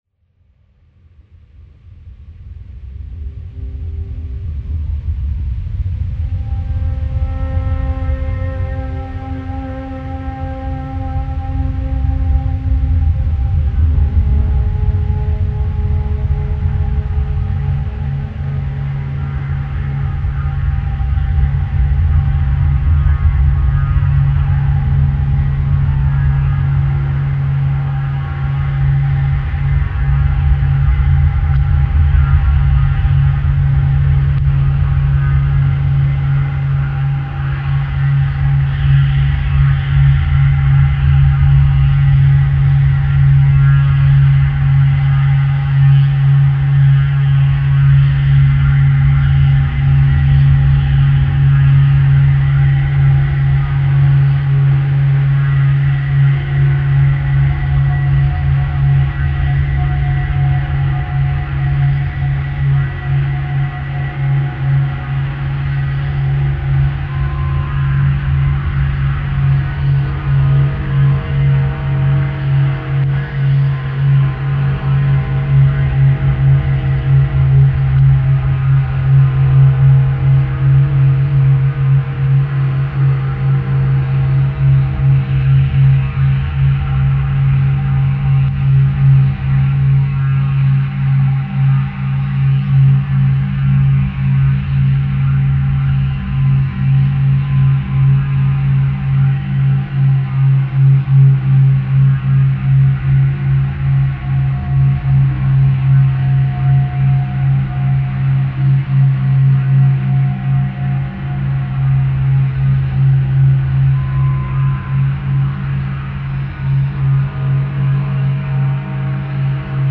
File under: Ambient